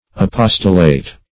Apostolate \A*pos"to*late\, n. [L. apostolatus, fr. apostolus.